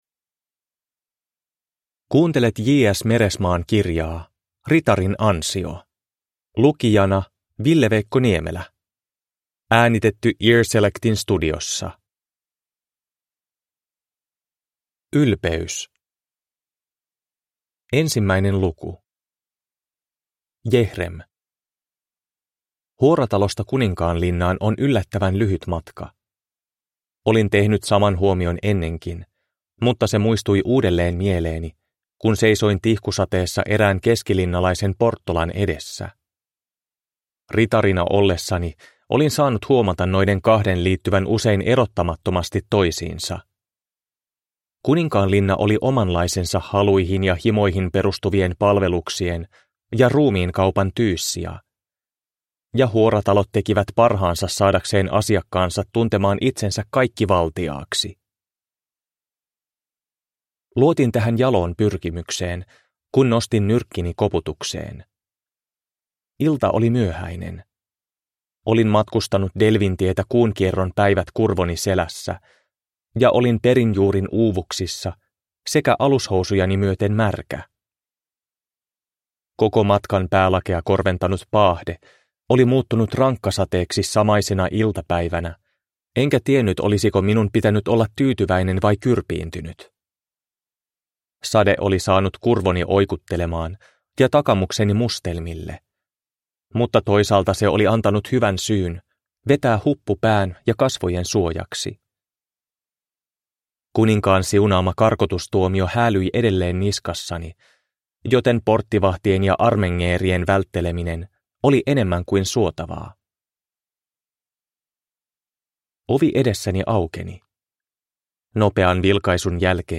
Ritarin ansio – Ljudbok